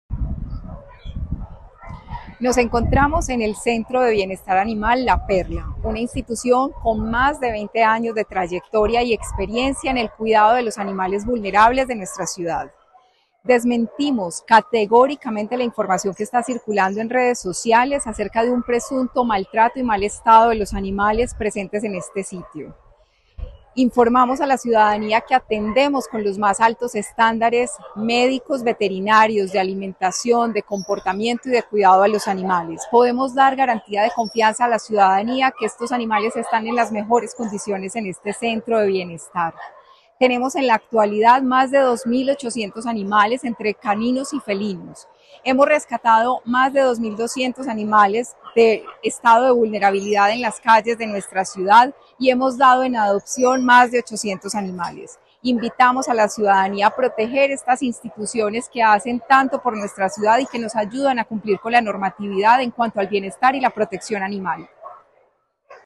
Declaraciones secretaria de Medio Ambiente, Marcela Ruiz
Declaraciones-secretaria-de-Medio-Ambiente-Marcela-Ruiz.mp3